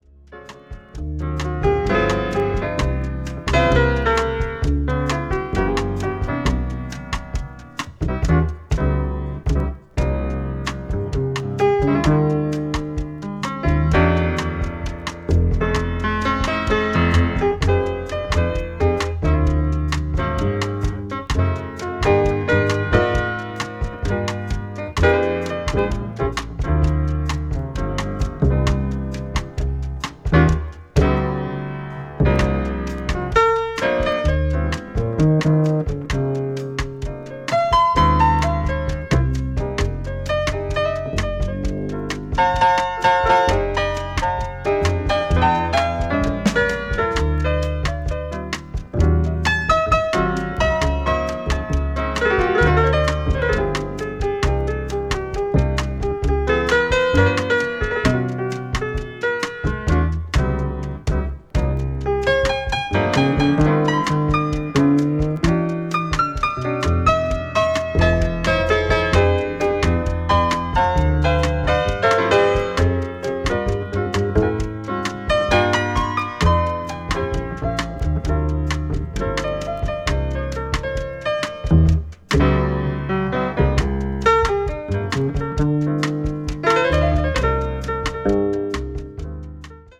contemporary jazz   cool jazz   modern jazz   mood jazz